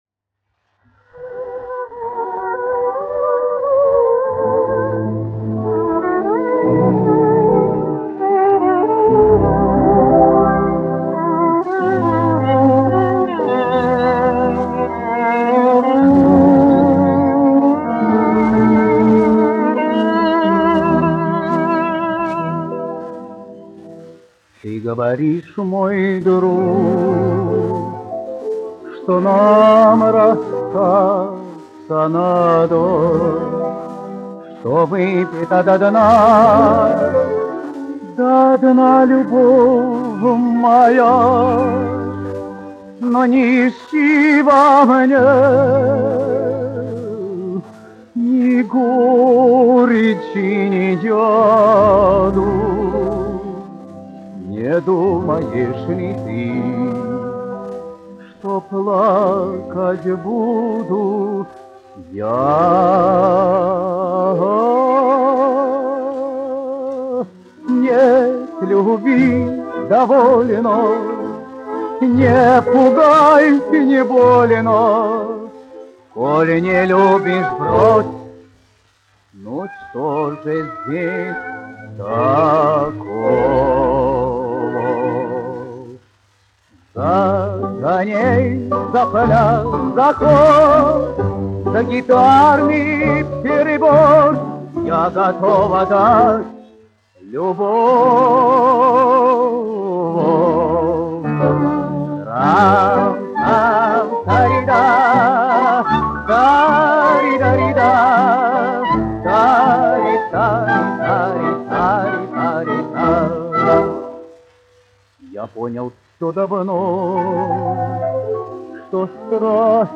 1 skpl. : analogs, 78 apgr/min, mono ; 25 cm
Romances (mūzika)
Latvijas vēsturiskie šellaka skaņuplašu ieraksti (Kolekcija)